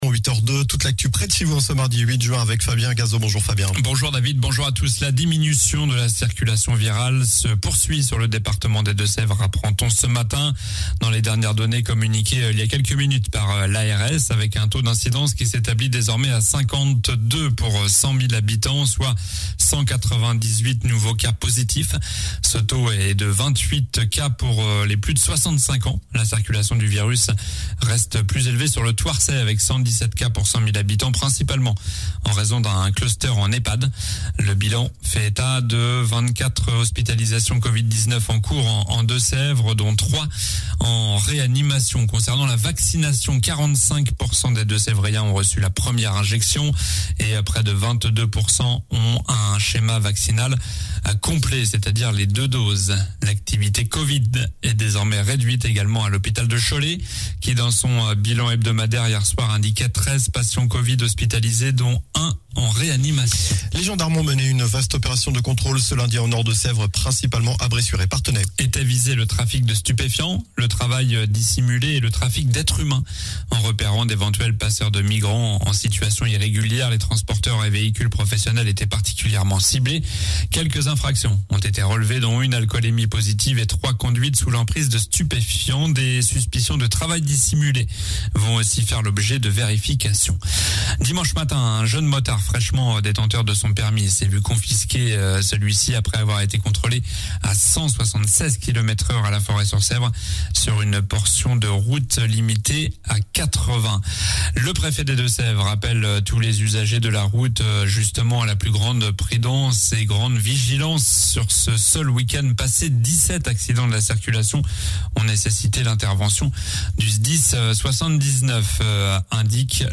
JOURNAL DU MARDI 08 JUIN (MATIN)